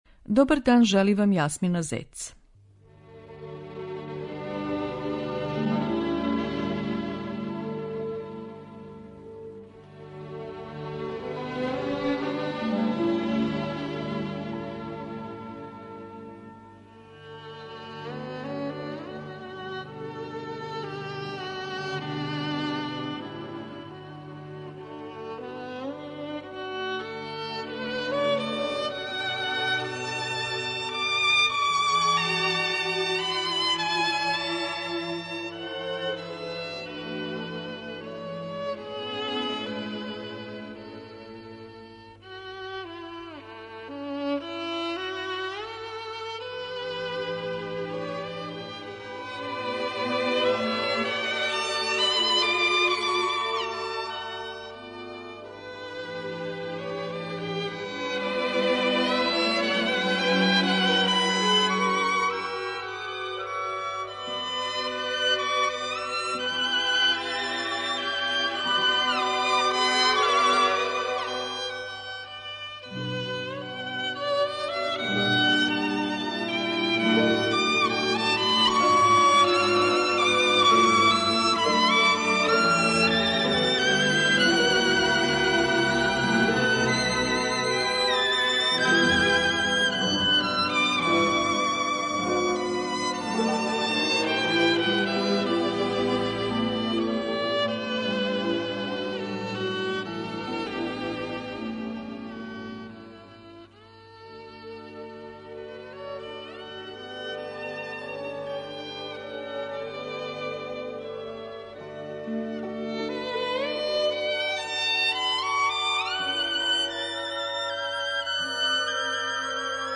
Слушаоци Радио Београда 2 ће моћи да чују раритетне снимке послављеног виолинисте, који је често наступао у Београду.